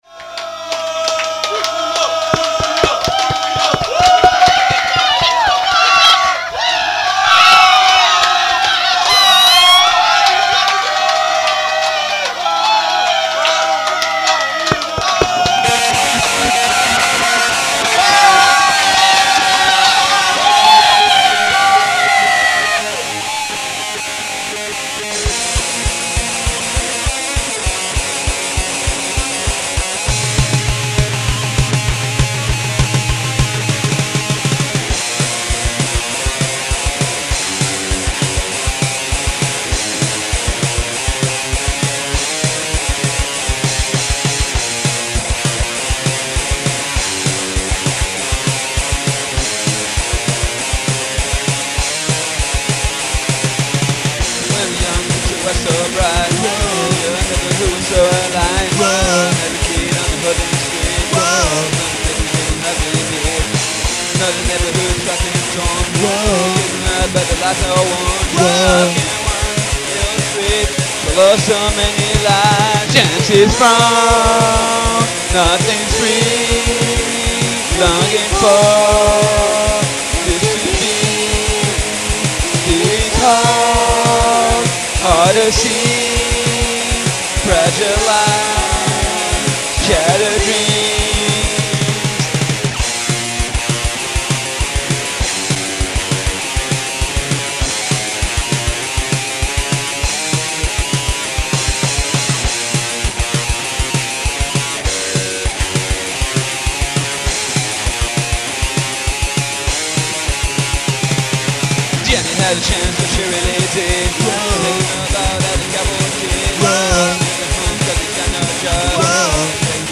Live
Batterie : Guitar Pro 4